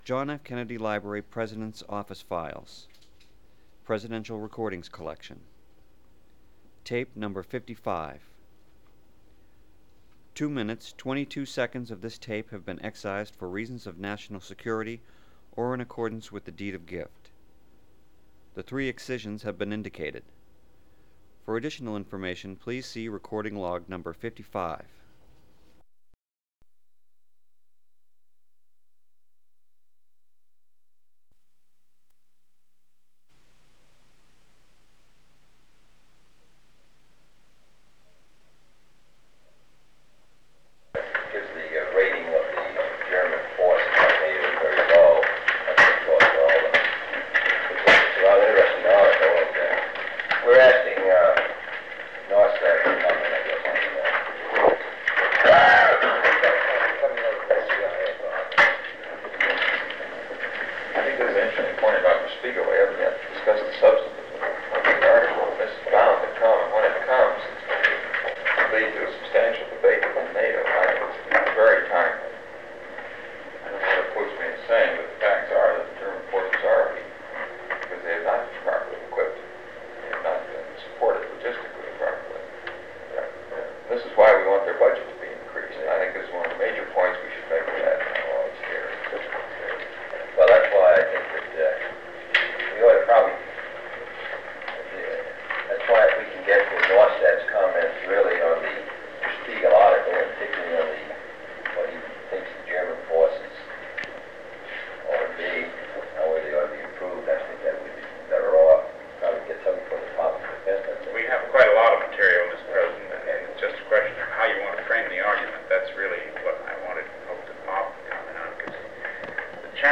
Meeting on Konrad Adenauer’s Visit
Secret White House Tapes | John F. Kennedy Presidency Meeting on Konrad Adenauer’s Visit Rewind 10 seconds Play/Pause Fast-forward 10 seconds 0:00 Download audio Previous Meetings: Tape 121/A57.